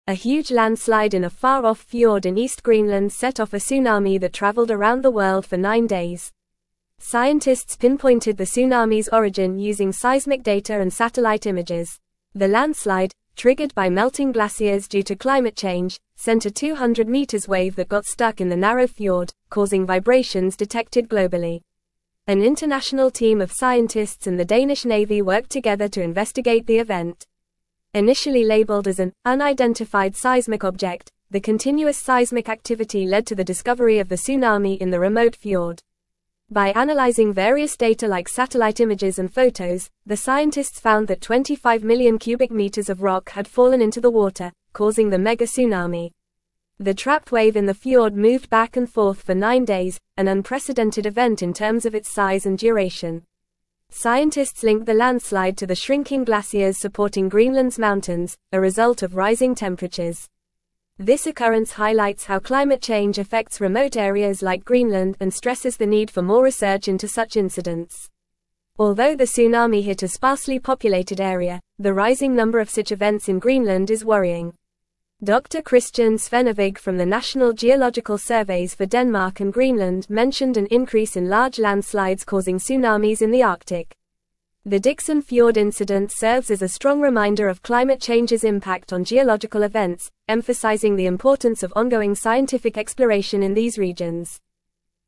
Fast
English-Newsroom-Upper-Intermediate-FAST-Reading-Greenland-Tsunami-Scientists-Unravel-Cause-of-Mysterious-Tremors.mp3